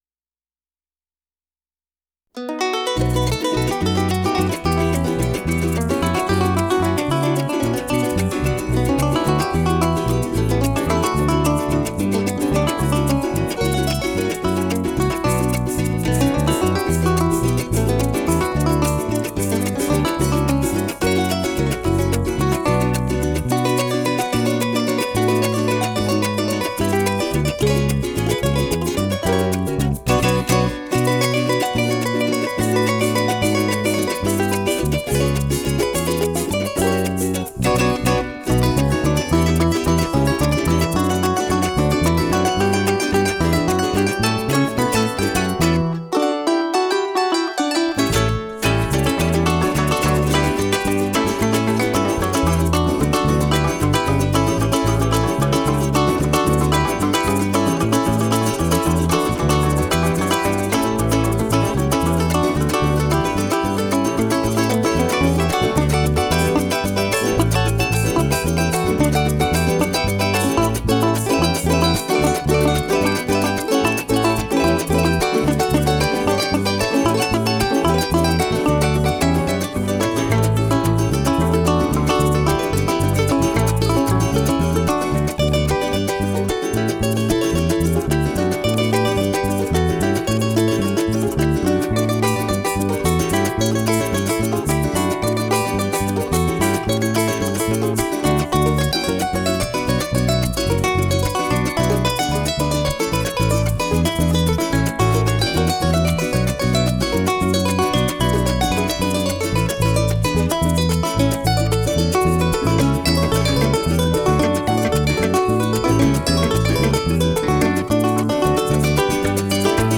9. seis por derecho - Golpe Tradicional .wav (73.17 MB)